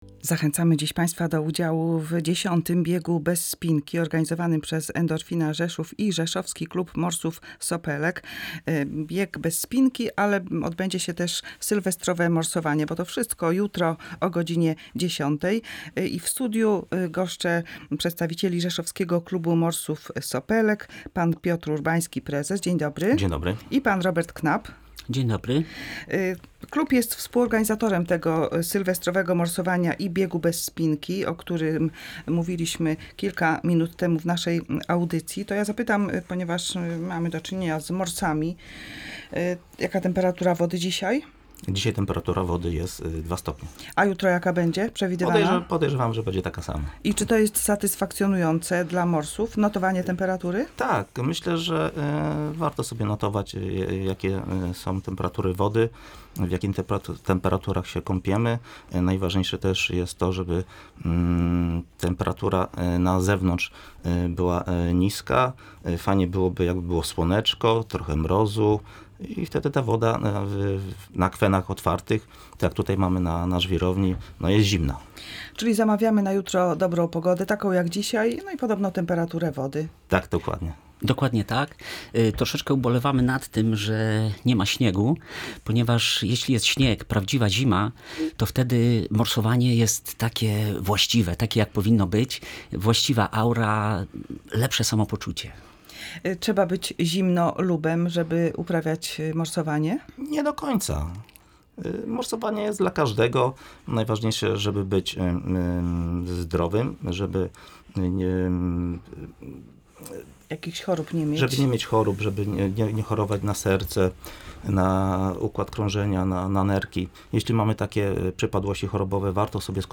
Opowiadali również o tym, jak kąpiel w lodowatej wodzie polepsza humor, podnosząc poziom endorfin i adrenaliny.